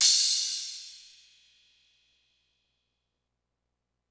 Crashes & Cymbals
TM88 LowCrash.wav